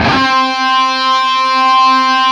LEAD C 3 LP.wav